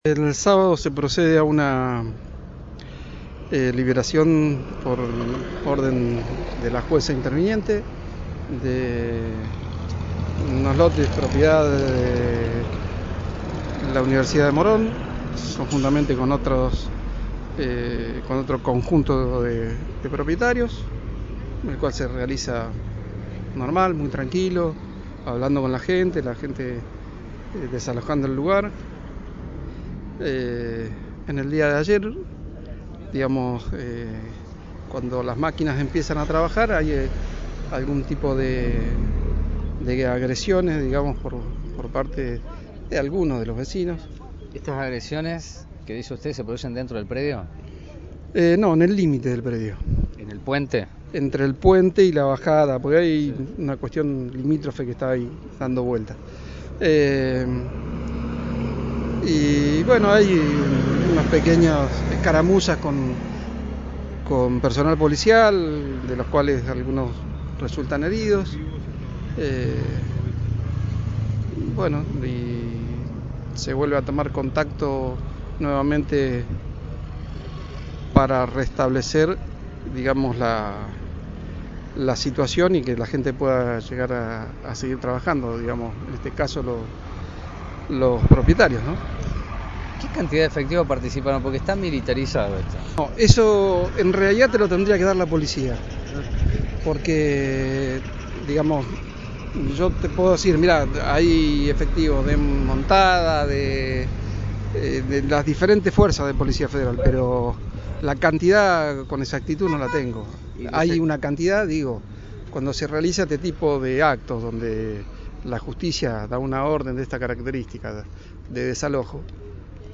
PALABRAS DEL SECRETARIO DE SEGURIDAD –